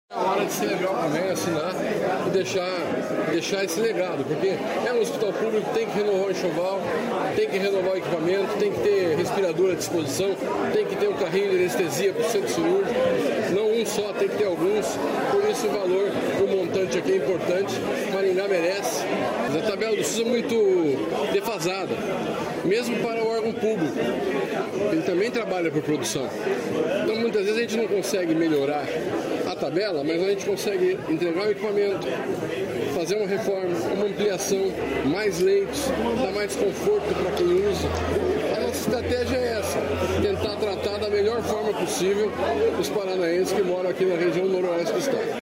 Sonora do secretário da Saúde, Beto Preto, sobre equipamentos no Hospital Municipal de Maringá